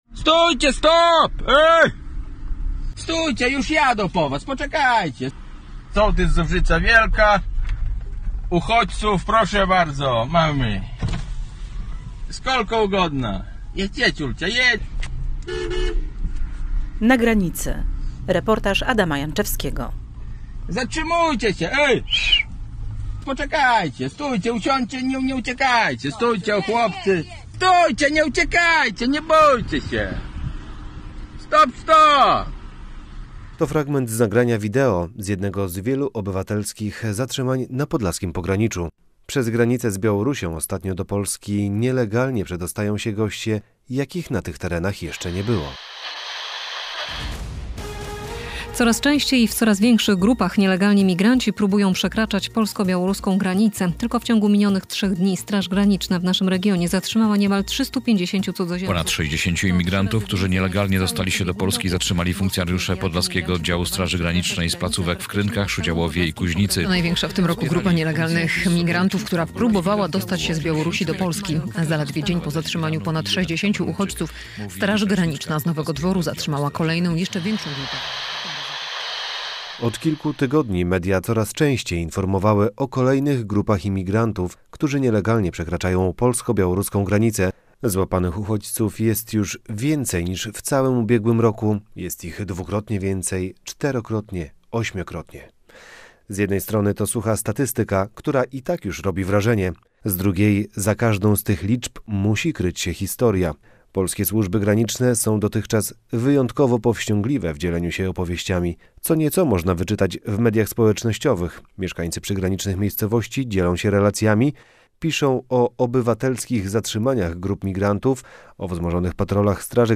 Mieszkańcy przygranicznych, spokojnych dotychczas wsi, czegoś takiego jeszcze w życiu nie widzieli. Posłuchaj reportażu: Nazwa Plik Autor “Na granicy”